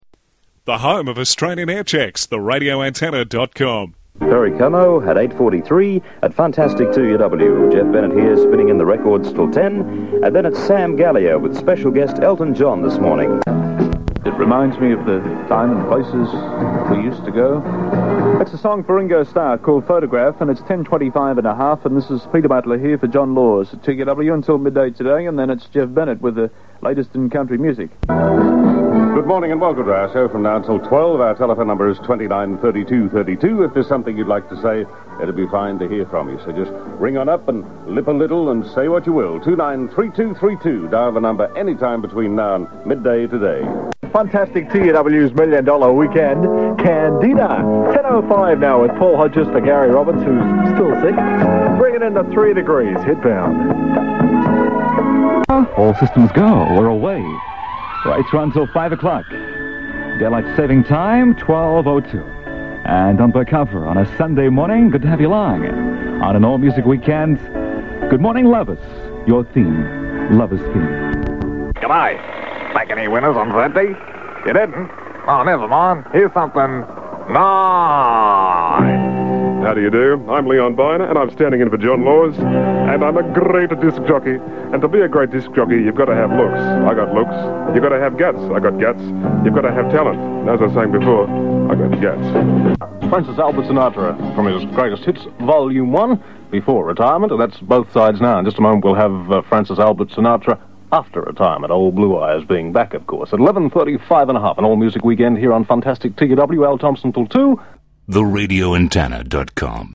Radio Aircheck- Funtastic 2UW 1970’s various jocks pt 2